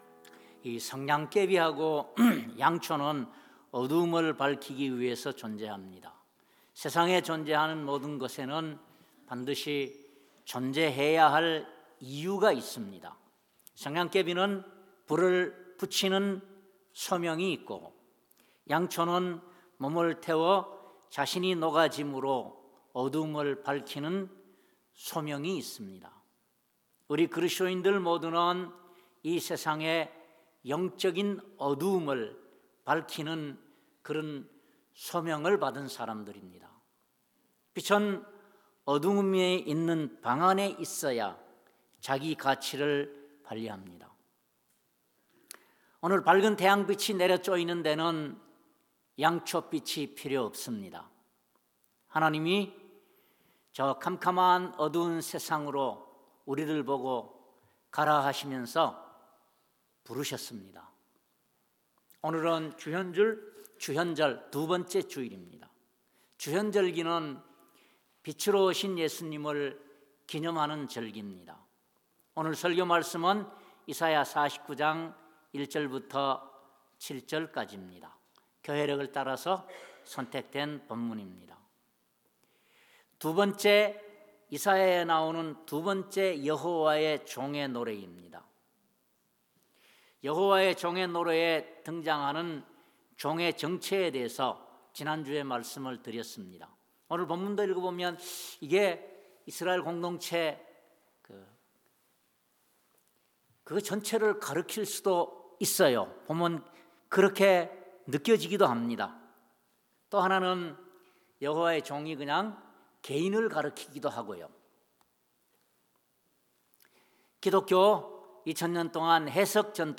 이사야 49:1-7 Service Type: 주일예배 첫번째로 여호와의 종이 메시야라고 한다면 그는 어떤 신분을 가지고 있고 하나님과의 관계는 어떤 관계입니까?